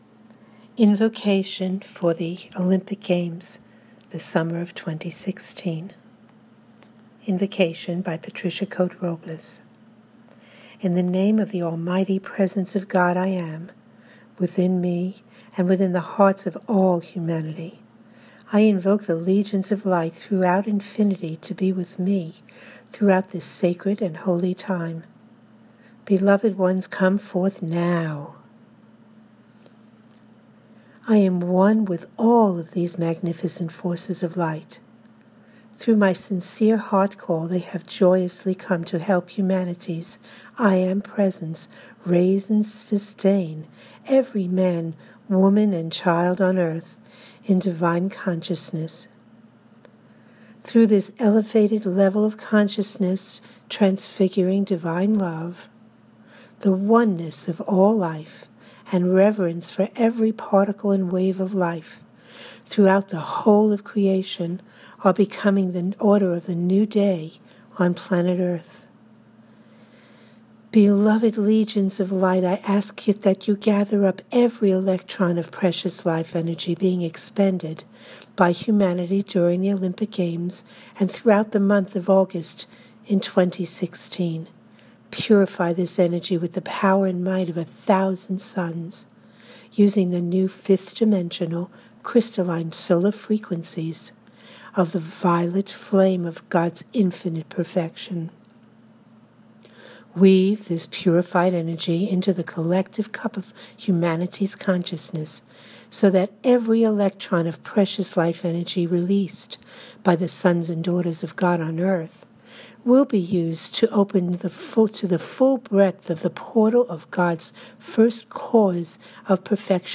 INVOCATION
invocation-for-august-2016.m4a